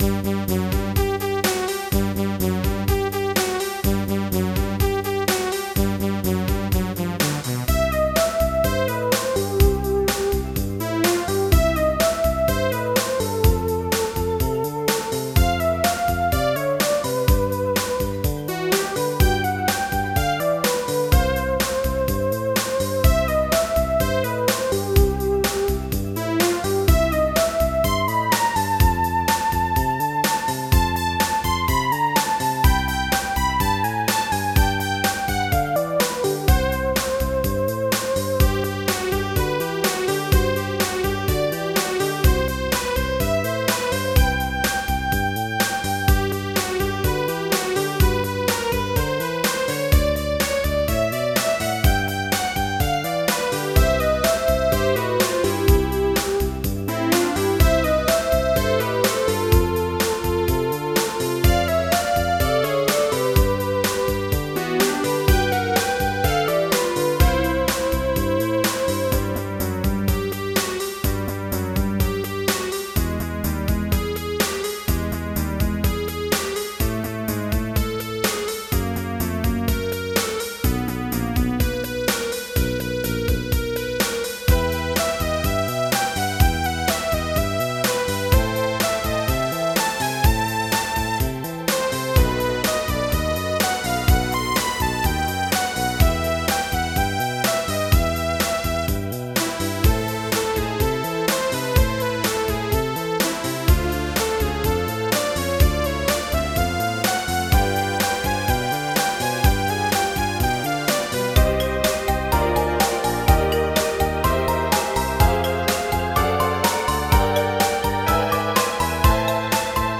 Title and Ingame (AWE) (40k)